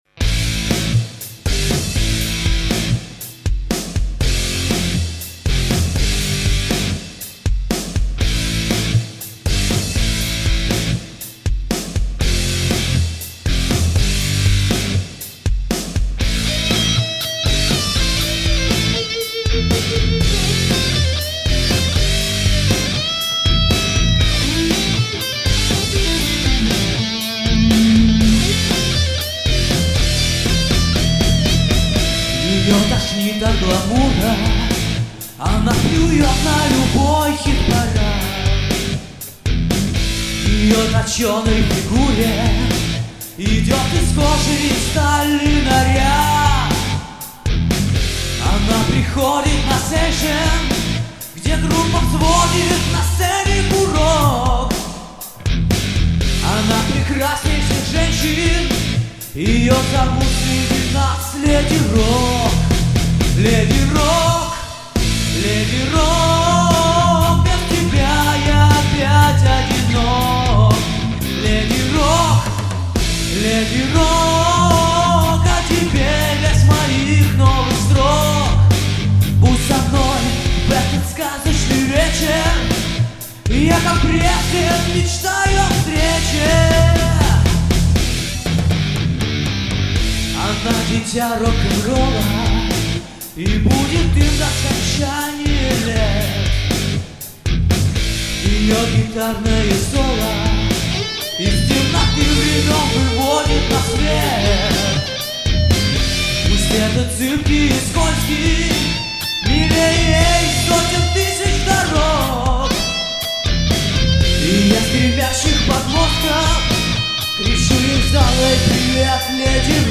рок-демо
Мужской
Сочиняю,пою,играю на гитаре.